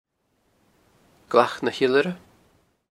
Gaelic name: Glac Sìthean na h-Iolaire Name in Original Source: Glach Shehan na Funnaraich/Glach Sean na Eilaraich English meaning: Hollow of the knoll of the eagle Placename feature: Hollow Notes: It is possible that this name shows up the same dialectal pronounciation as Creag na h-Iolaire (q.v.) which is heard referred to as creak-un na fyee-ull-ir-uh.